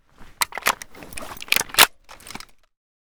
ak74m_reload.ogg